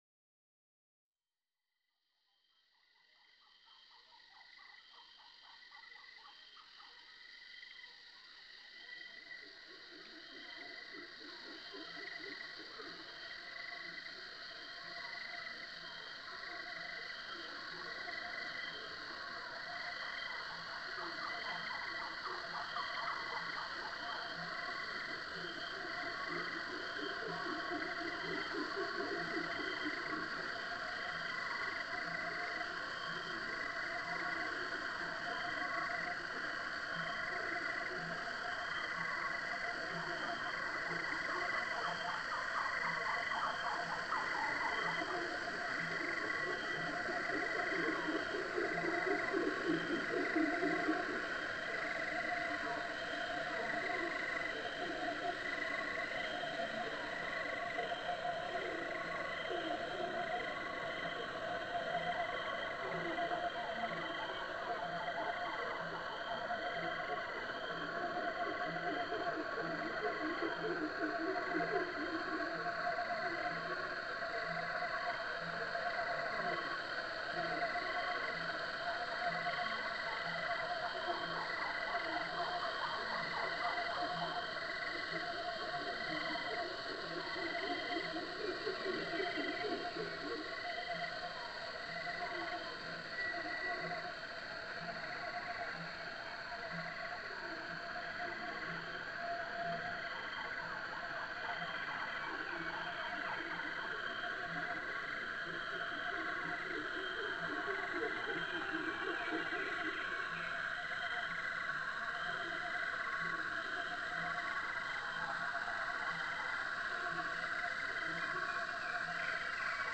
ASH_NOISE_binaural_radio.mp3